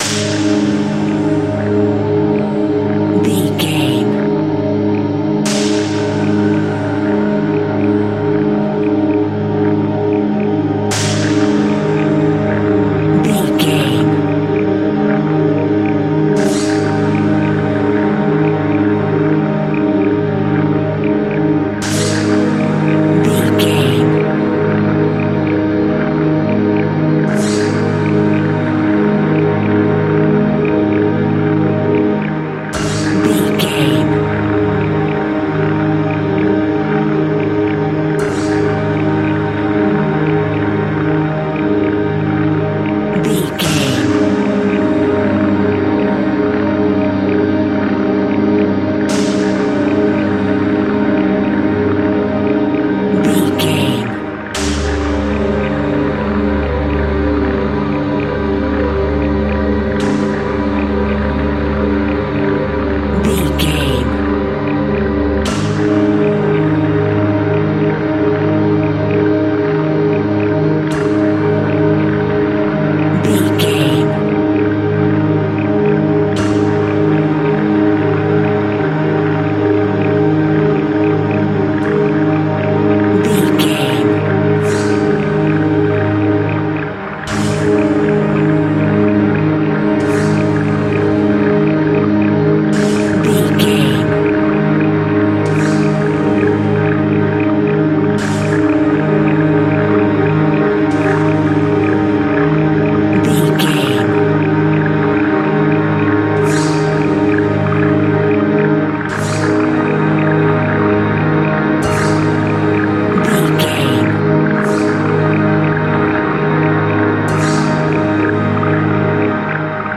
Atonal
E♭
Slow
tension
ominous
eerie
synthesiser
percussion
Horror Ambience
Synth Pads
Synth Ambience